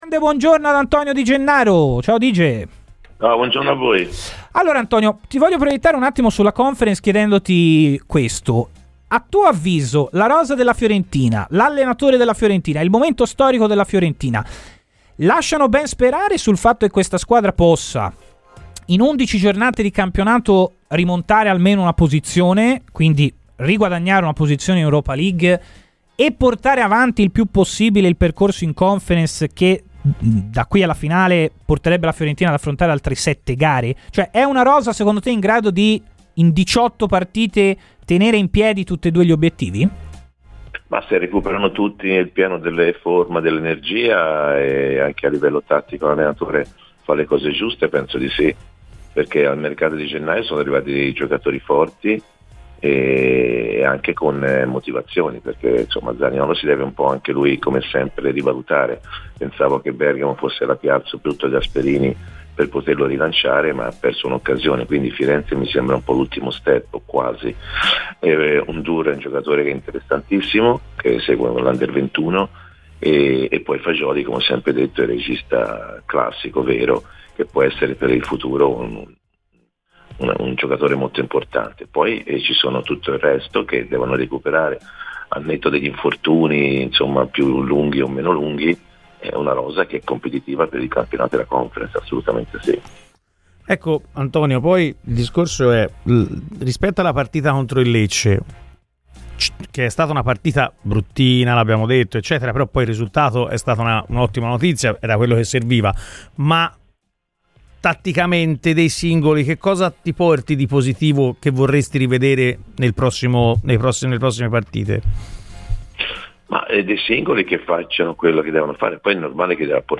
Antonio Di Gennaro, ex centrocampista della Fiorentina, è intervenuto a Radio FirenzeViola nel corso di "Chi si compra?" per parlare del momento attuale della squadra di Palladino: "Se recuperano tutti sul piano della forma e l’allenatore fa le cose giuste, penso che la Fiorentina possa rimontare almeno una posizione in classifica e portare avanti il più possibile il discorso in Conference.